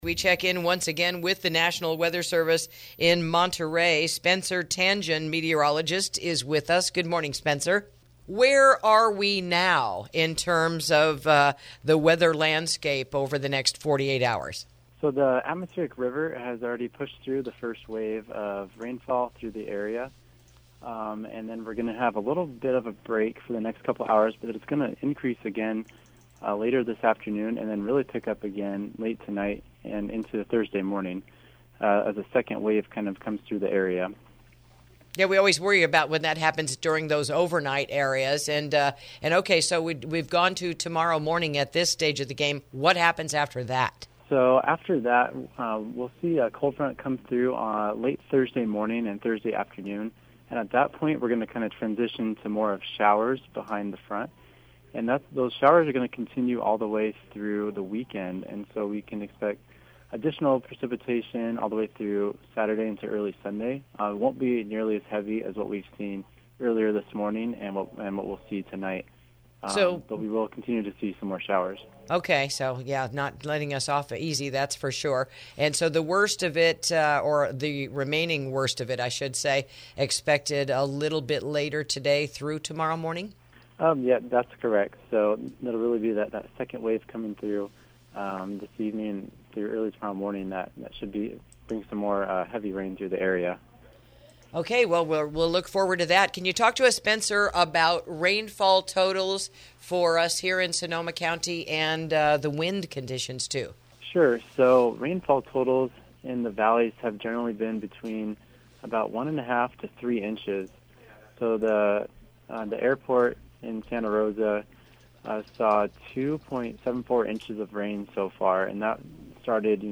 INTERVIEW: Russian River Flood Levels, Storm Update and Free Sandbags for Flooding Issues